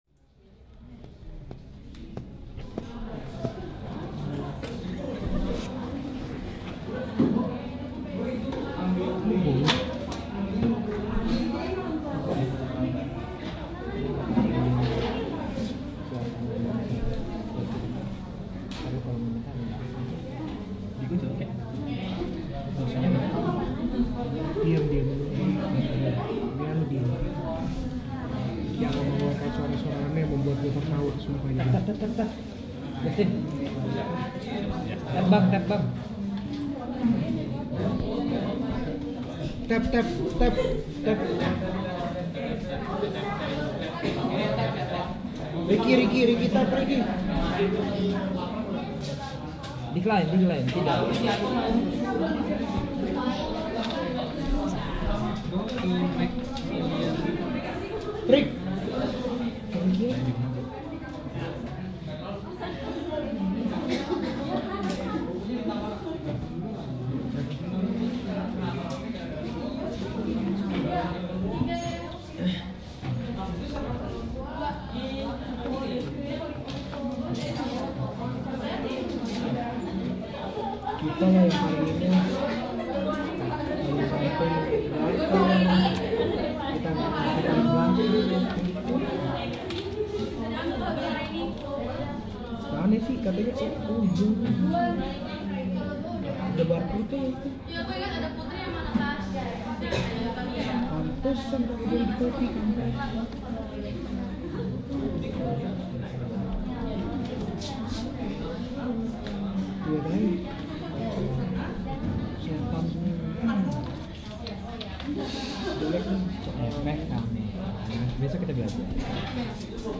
嘴里念念有词
Tag: mouthnoises 噪音 openmouth